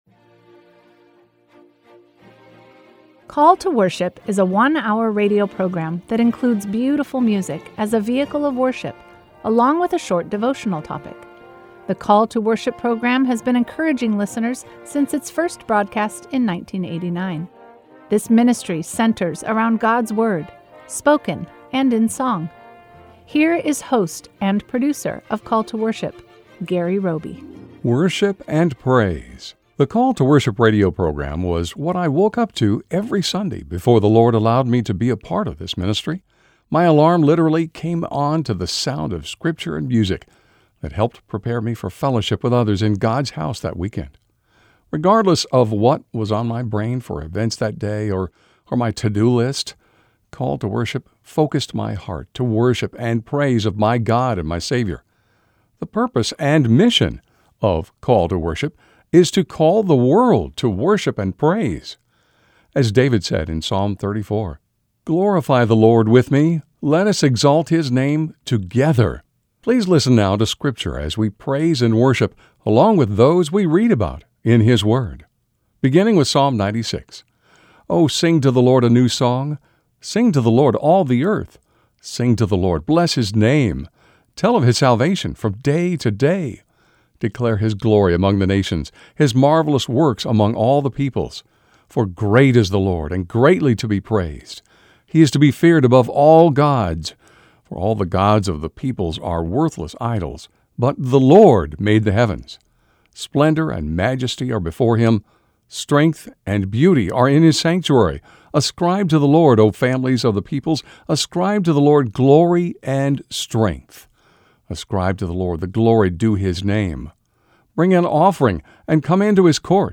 The sound of scripture and music within the Call To Worship radio broadcast helps thousands prepare for fellowship with others in God’s house each weekend. The purpose and mission of Call To Worship is to call the world to Worship and Praise.